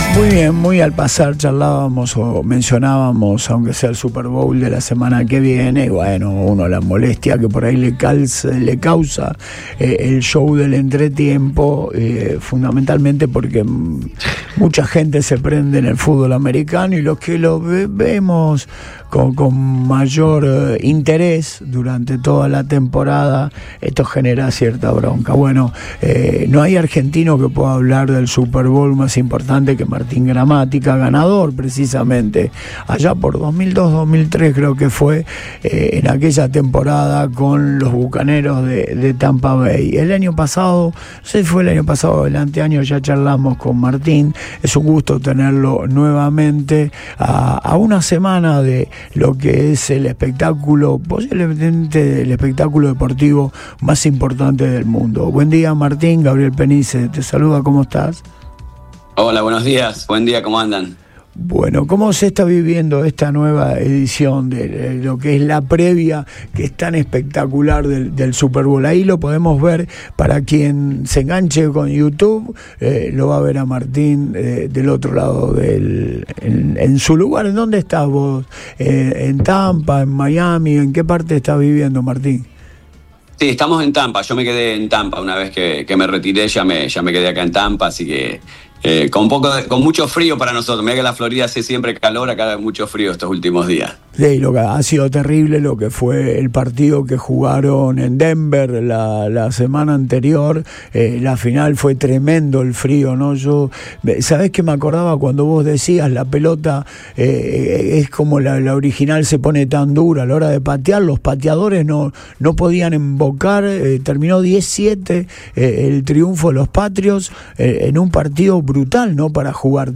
A una semana del espectáculo más grande del mundo, el único argentino campeón de la NFL charló con Antes de Todo y destacó la defensa de los Seahawks como la clave del partido y puso la lupa sobre el pateador venezolano de los Patriots.